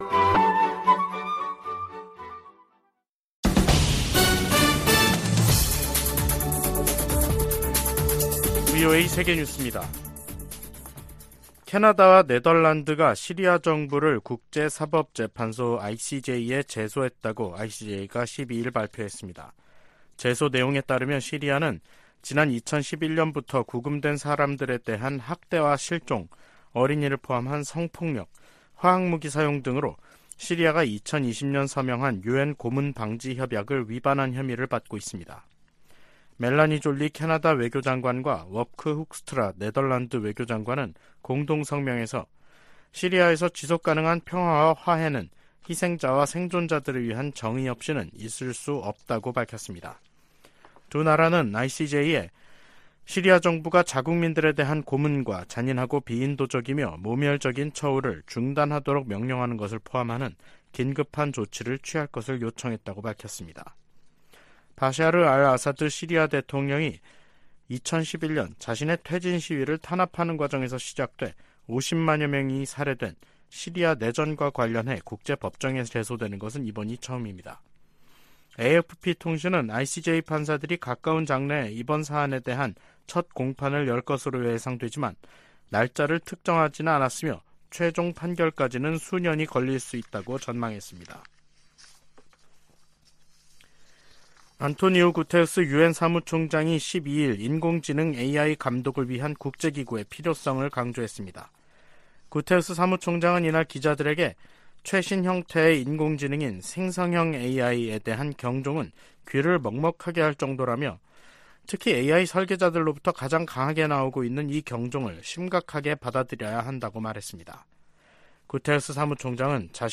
VOA 한국어 간판 뉴스 프로그램 '뉴스 투데이', 2023년 6월 13일 3부 방송입니다. 미국의 북핵 수석 대표는 워싱턴에서 한국의 북핵 수석대표와 회담한 후 북한의 추가 도발에 독자제재로 대응할 것이라는 입장을 밝혔습니다. 북한은 군사정찰위성 추가 발사 의지를 밝히면서도 발사 시한을 미리 공개하지 않겠다는 입장을 보이고 있습니다.